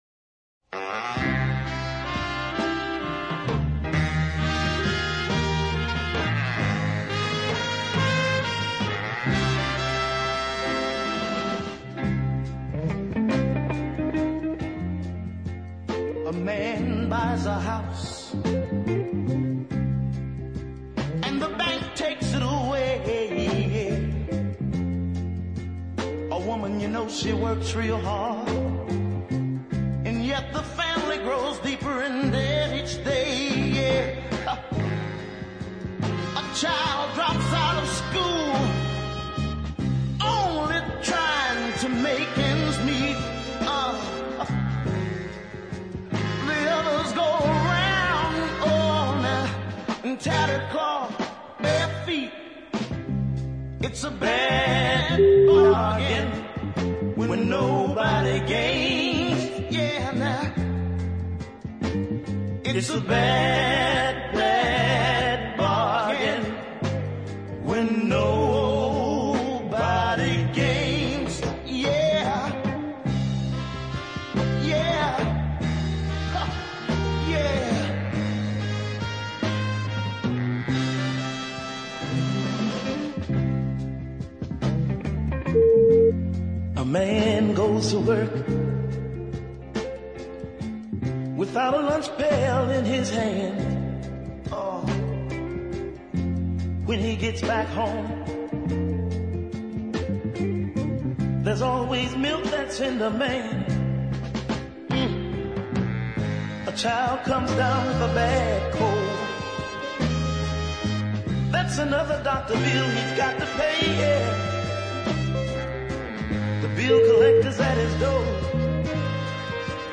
The horn rich ballad
gives a superlative reading of the doom laden lyric